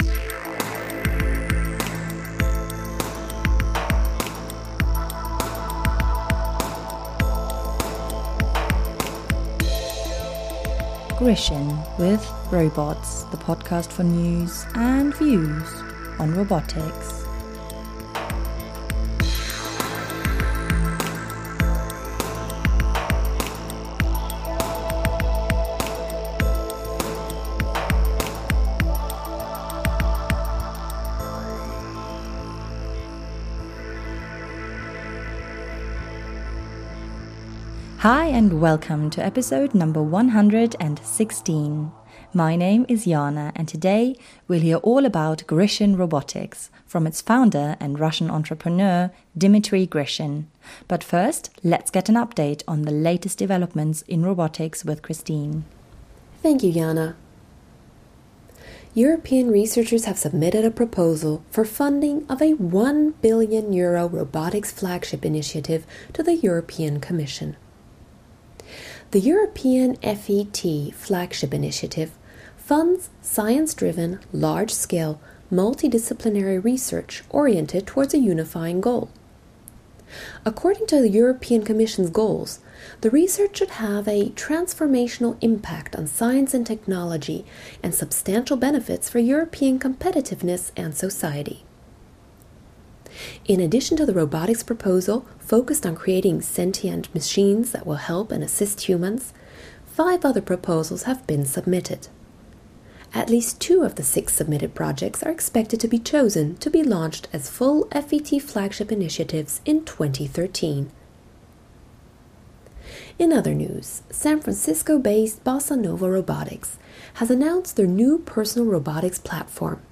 Интервью с Дмитрием Гришиным - основателем инвестиционной компании Grishin Robotics – RoboCraft